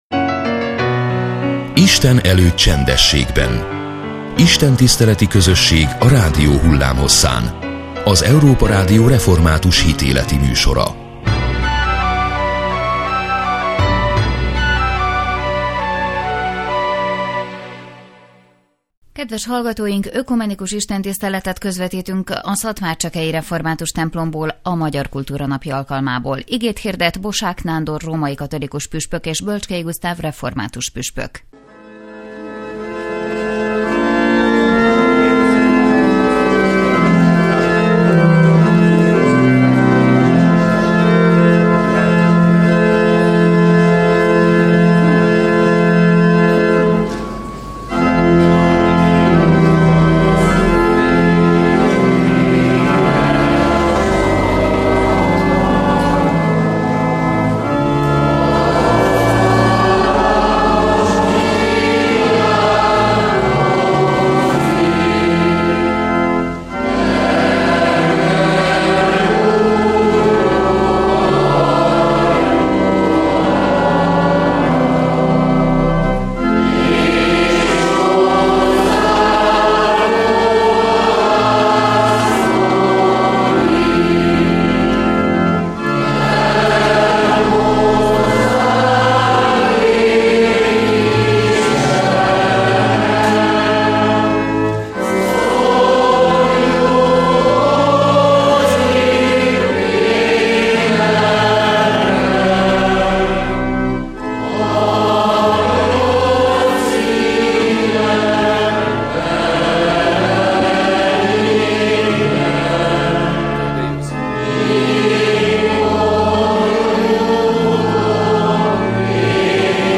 A Magyar Kultúra Napja alkalmából ökumenikus istentiszteletre gyűltek össze a szatmárcsekei templomban vasárnap délelőtt. Először Bosák Nándor római katolikus püspök hirdetett igét.